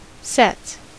Ääntäminen
Synonyymit (arkikielessä) set theory Ääntäminen US : IPA : [sɛts] Haettu sana löytyi näillä lähdekielillä: englanti Käännöksiä ei löytynyt valitulle kohdekielelle.